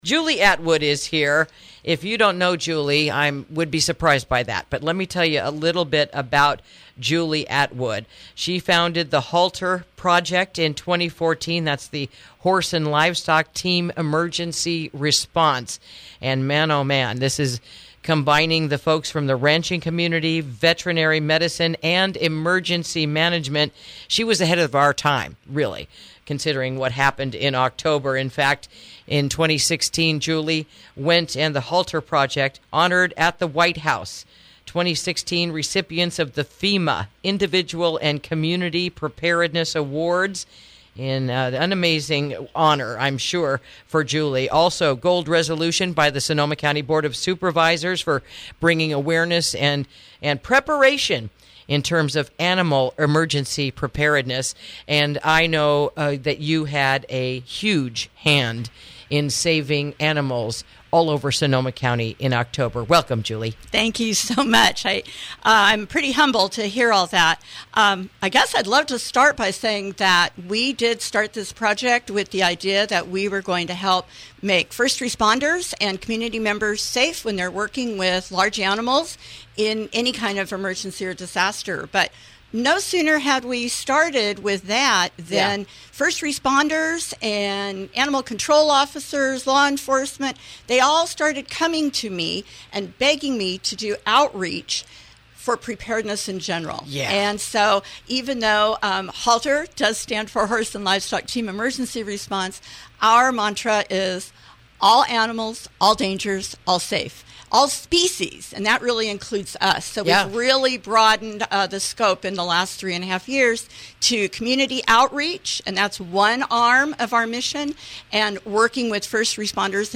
Interview: The Benefits of Attending the Upcoming Home and Ranch Readiness Summit